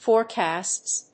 /fɔˈrkæsts(米国英語), fɔ:ˈrkæsts(英国英語)/